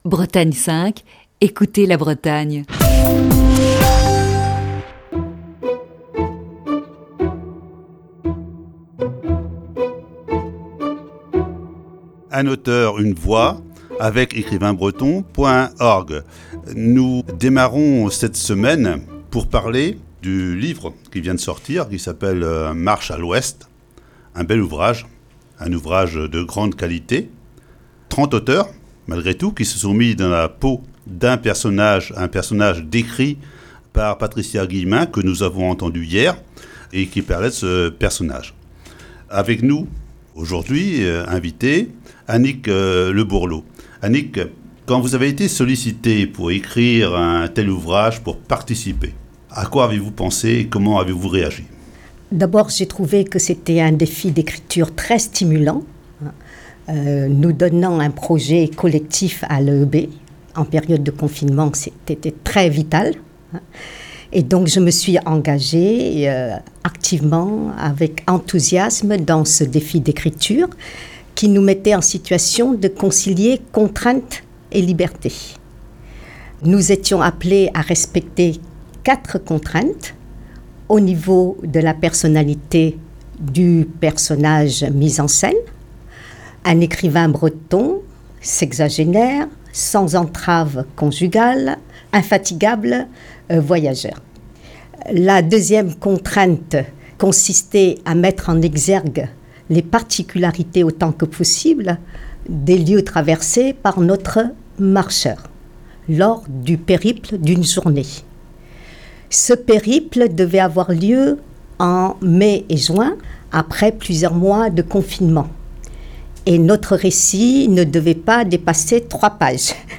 Chronique du 23 mars 2021.